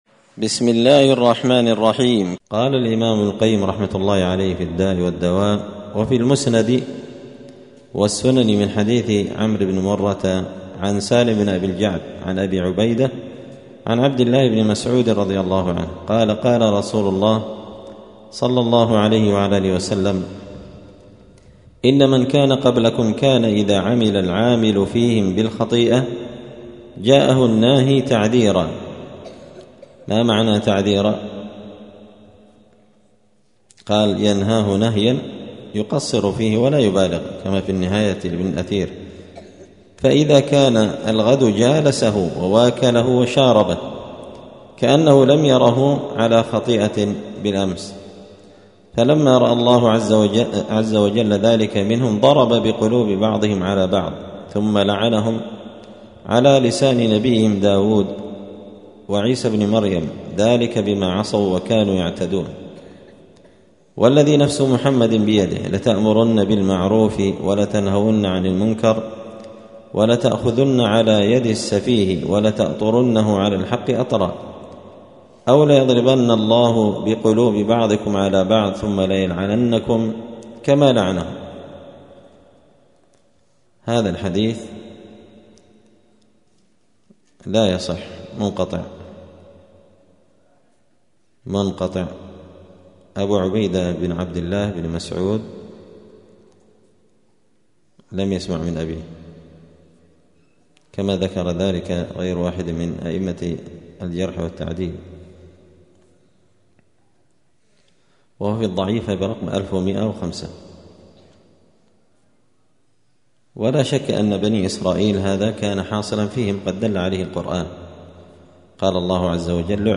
الداء والدواء للإمام ابن القيم رحمه الله الدرس التاسع عشر (19) تابع لأحاديث وآثار في أنواع العقوبات التي نزلت بالأفراد والأمم في الدنيا بسبب معاصيهم
تعليق وتدريس الشيخ الفاضل: